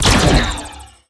shotgun01.wav